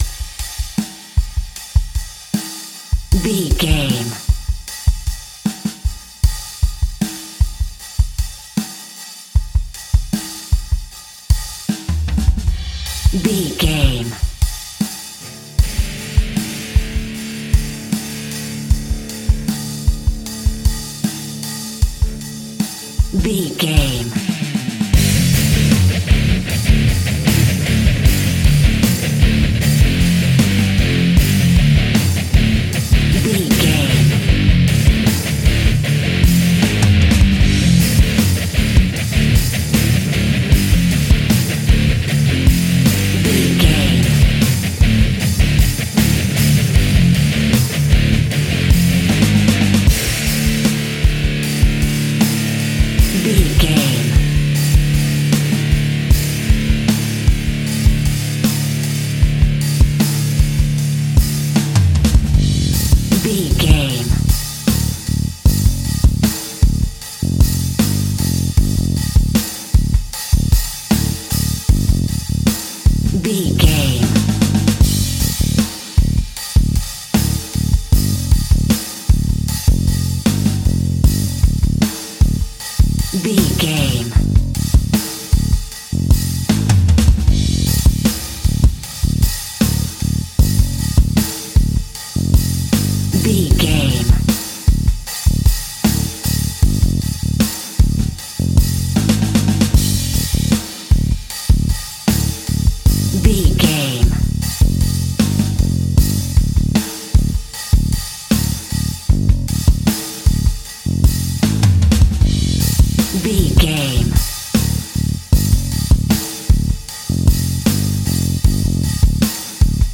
Powerful Rock Metal Death Alt Mix.
Epic / Action
Fast paced
Aeolian/Minor
heavy metal
distortion
instrumentals
Rock Bass
heavy drums
distorted guitars
hammond organ